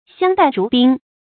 相待如宾 xiāng dài rú bīn 成语解释 相处如待宾客。形容夫妻互相尊敬。